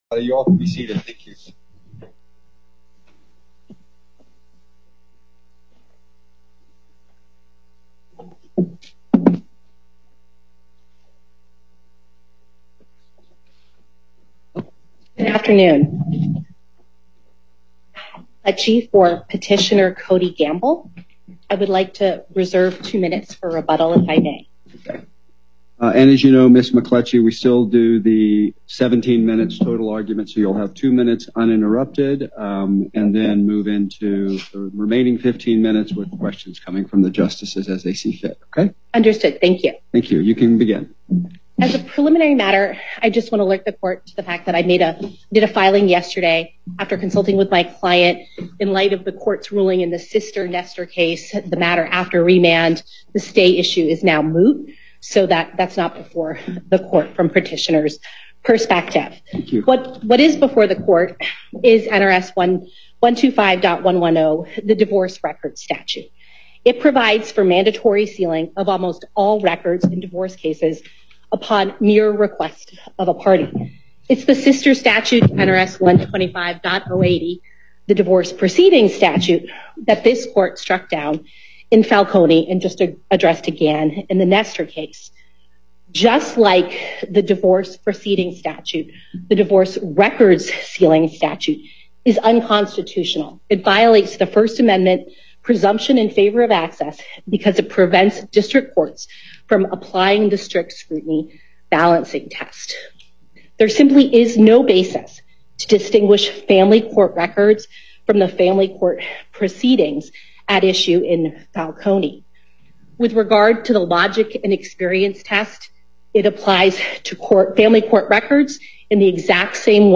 Before the En Banc Court, Chief Justice Herndon presiding